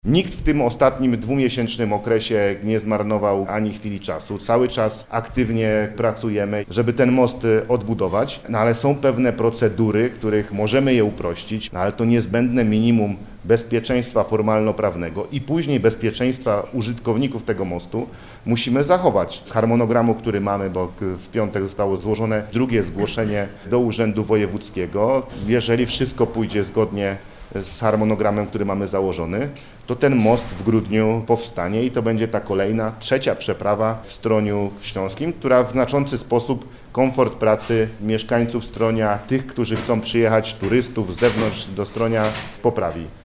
– skomentował podczas spotkania z dziennikarzami Marszałek Paweł Gancarz.